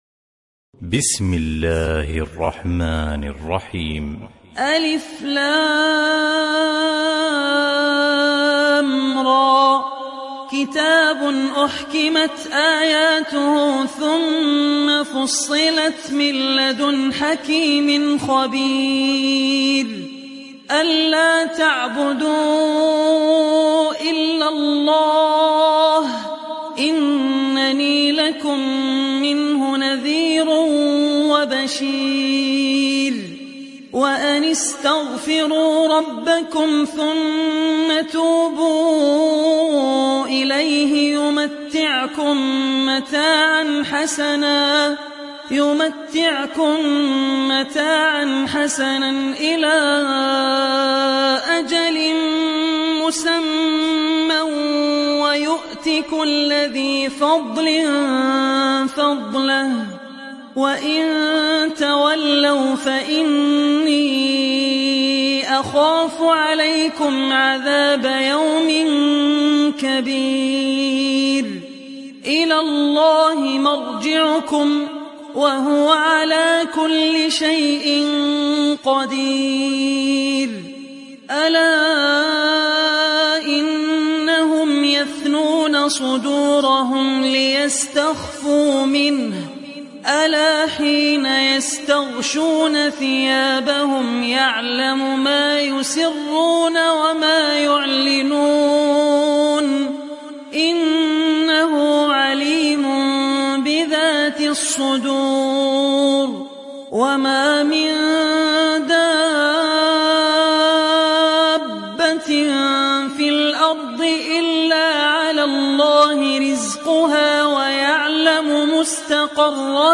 Sourate Hud mp3 Télécharger Abdul Rahman Al Ossi (Riwayat Hafs)